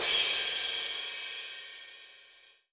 Crashes & Cymbals
DY StruggleCrash.wav